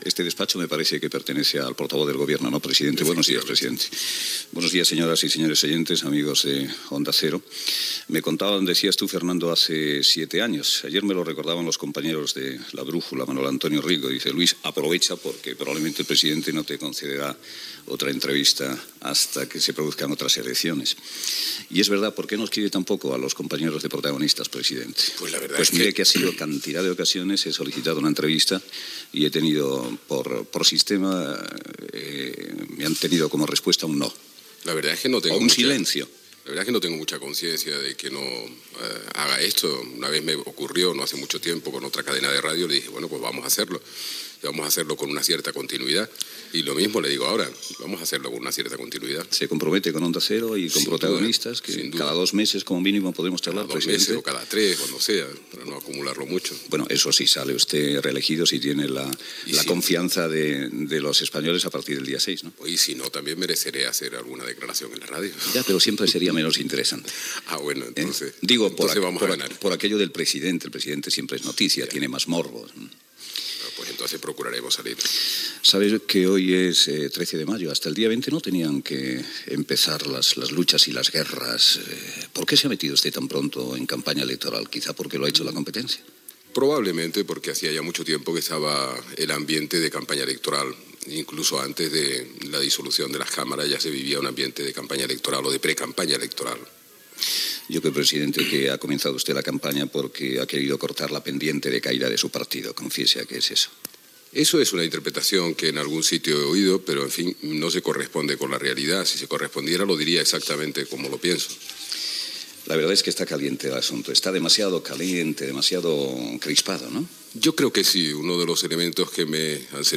Fragment d'una entrevista al president del govern espanyol, Felipe González al Palau de la Moncloa.